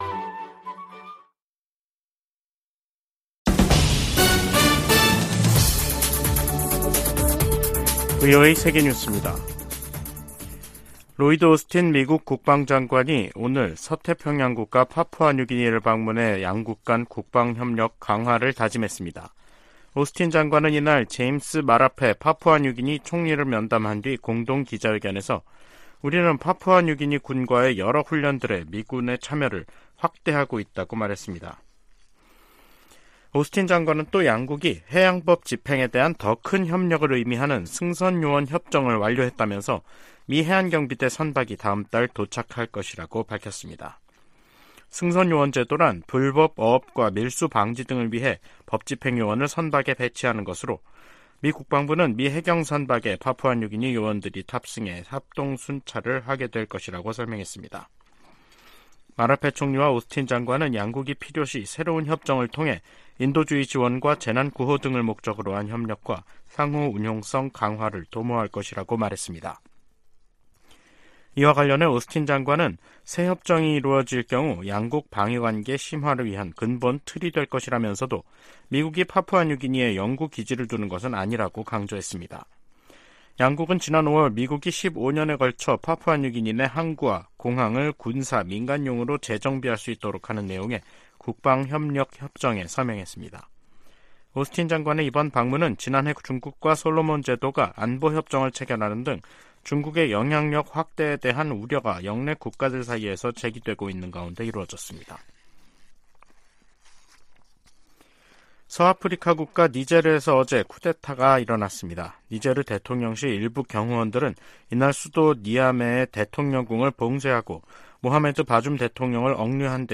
VOA 한국어 간판 뉴스 프로그램 '뉴스 투데이', 2023년 7월 27일 2부 방송입니다. 김정은 북한 국무위원장이 러시아 국방장관과 함께 '무장장비 전시회-2023'를 참관했습니다. 백악관은 북한과 러시아의 무기 거래 가능성에 관해 누구도 러시아의 우크라이나 전쟁을 지원해서는 안된다고 강조했습니다. 조 바이든 미국 대통령이 7월 27일을 한국전 정전기념일로 선포하고, 미한동맹이 세계 평화와 번영에 기여하도록 노력하자고 말했습니다.